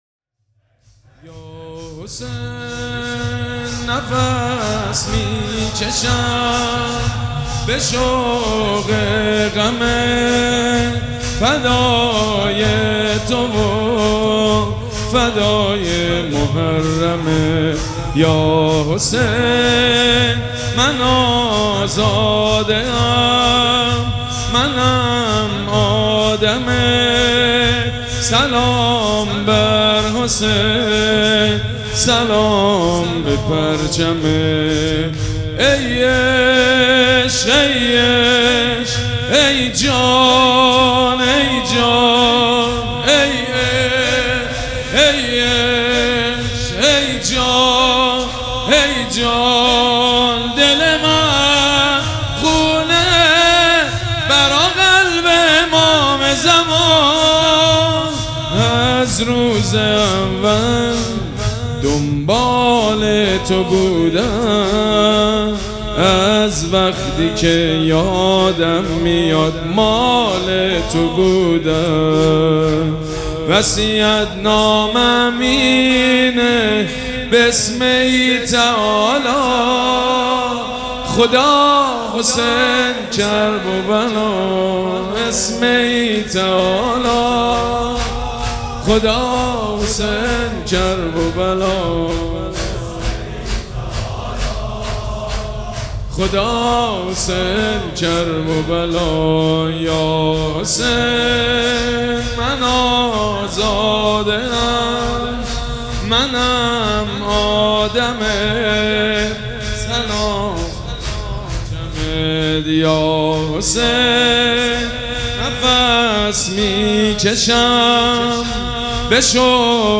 شهادت امام صادق علیه السلام
حسینیه ی ریحانه الحسین (س)
شور
مداح
شور سوم.mp3